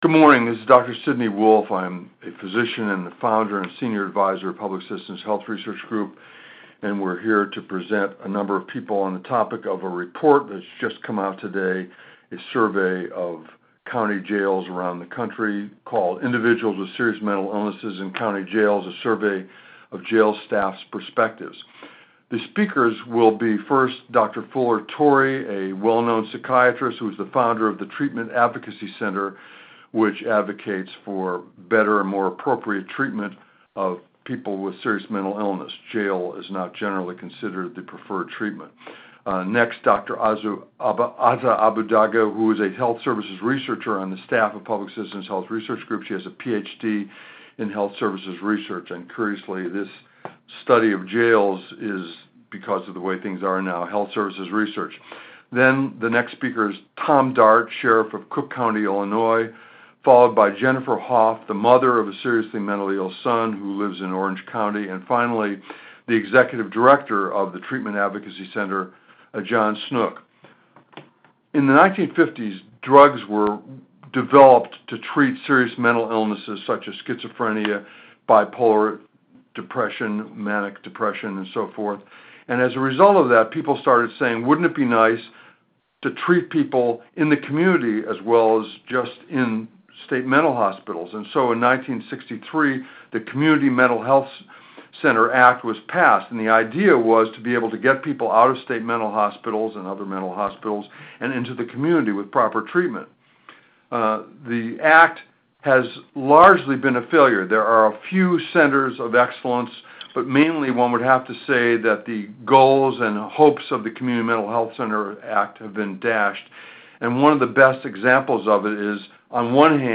Recording of the press conference